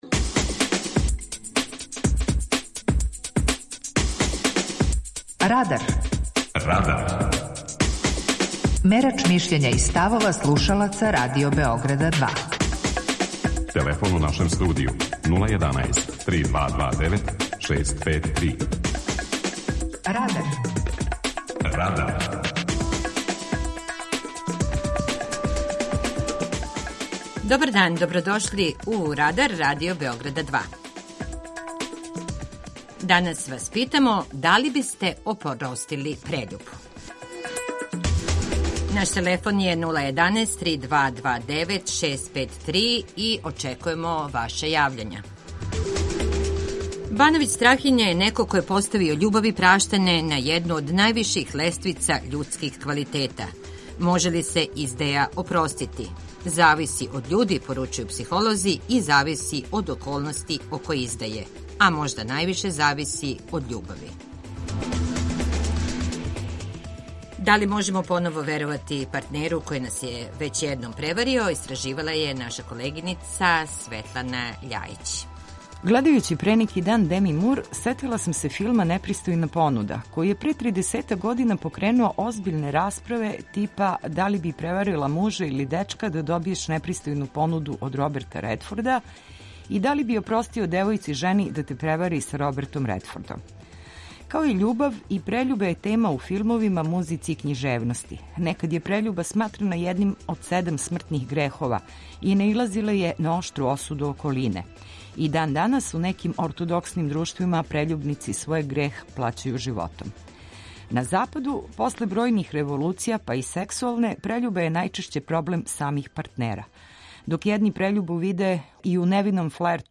Данашње питање за наше слушаоце је: да ли бисте опростили прељубу? преузми : 18.96 MB Радар Autor: Група аутора У емисији „Радар", гости и слушаоци разговарају о актуелним темама из друштвеног и културног живота.